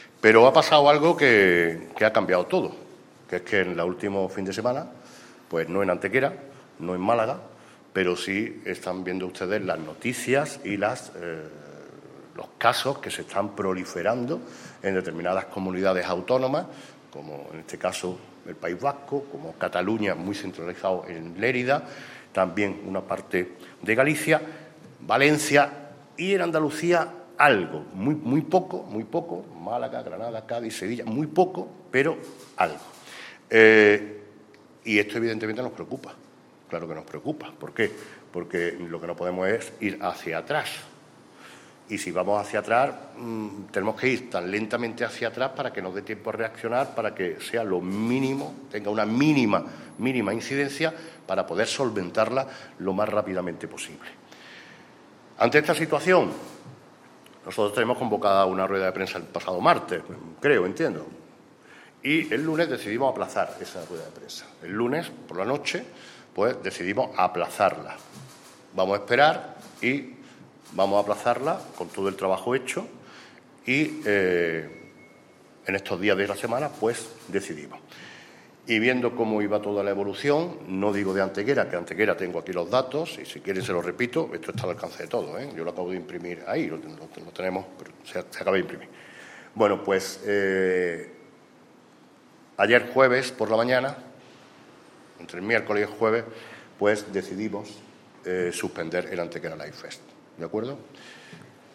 El alcalde de Antequera, Manolo Barón, y el teniente de alcalde delegado de Patrimonio Mundial, Juan Rosas, han anunciado en la mañana de hoy viernes en rueda de prensa la cancelación del Antequera Light Fest (ALF), festival de nuevas tecnologías, luz y sonido que cada año a mediados del mes de julio conmemora en nuestra ciudad la declaración del Sitio de los Dólmenes como Patrimonio Mundial de la UNESCO.
Cortes de voz